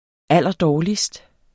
Udtale [ ˈalˀʌˈdɒːlisd ]